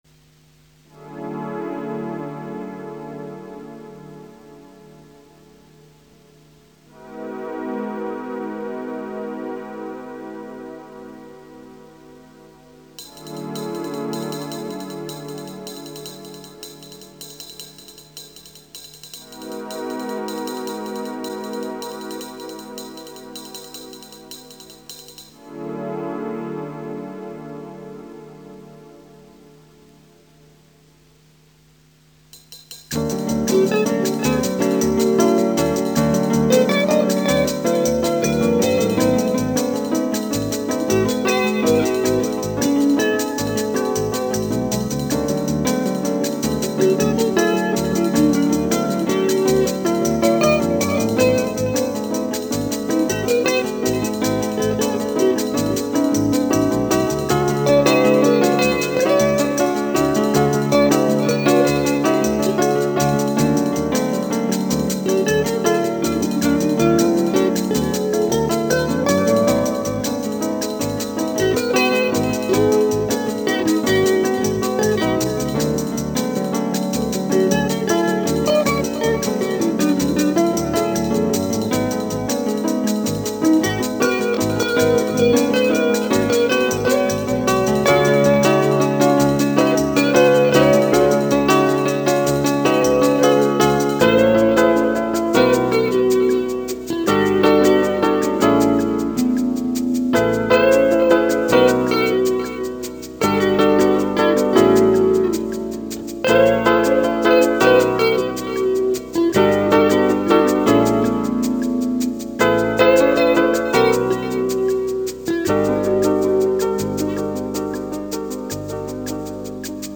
chitarra
tastiera